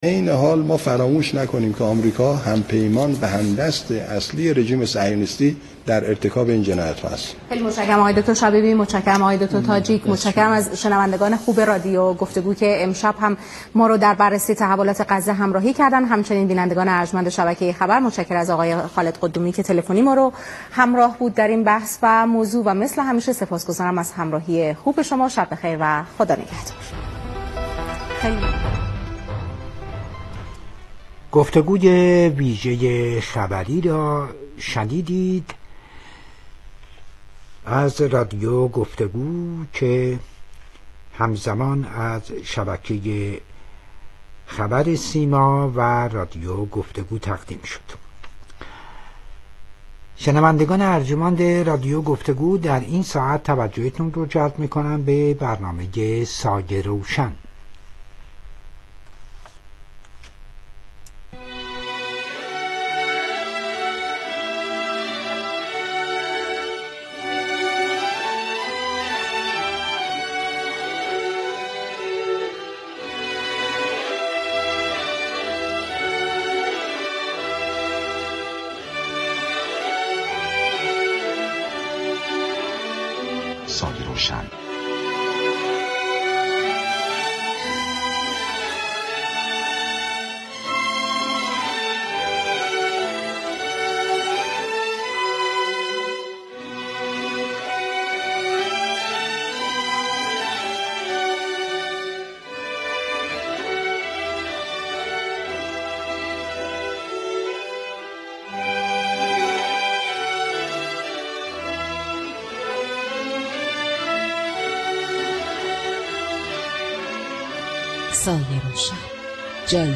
شهرتهران| با هم بشنویم| مصاحبه رادیویی با برنامه سایه روشن در خصوص آماده سازی زیرساختهای شهری برای جامعه معلولین